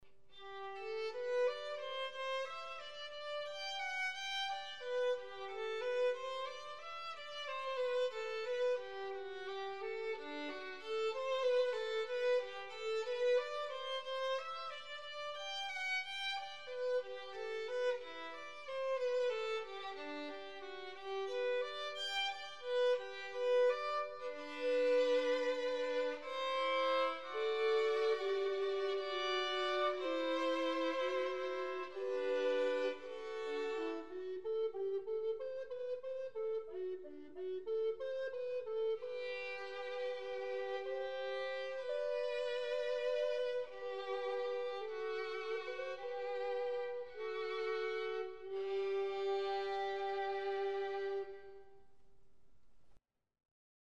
Please wait for the familiar "fanfare" before entering.